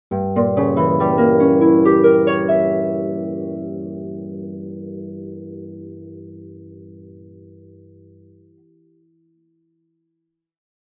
دانلود آهنگ تغییر صحنه سینمایی از افکت صوتی طبیعت و محیط
دانلود صدای تغییر صحنه سینمایی از ساعد نیوز با لینک مستقیم و کیفیت بالا
جلوه های صوتی